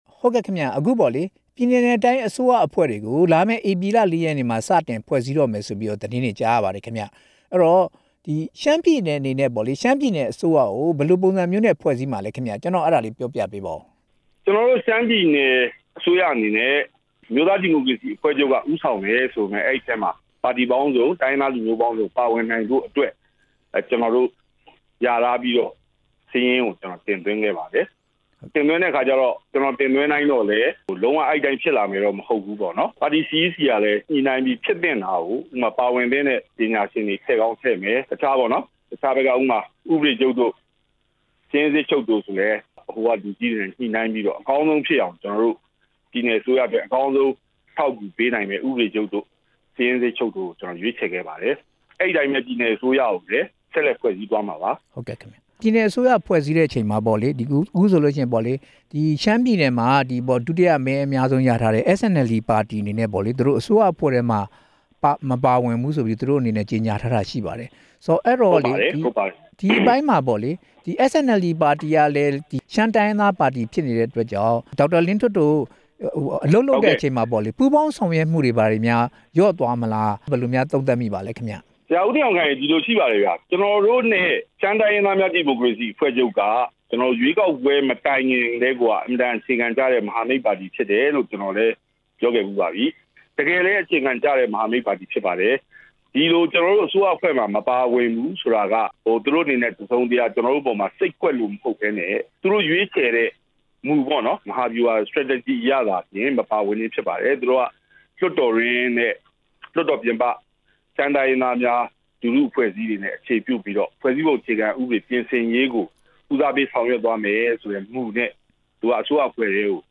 ဒေါက်တာလင်းထွဋ်နဲ့ မေးမြန်းချက်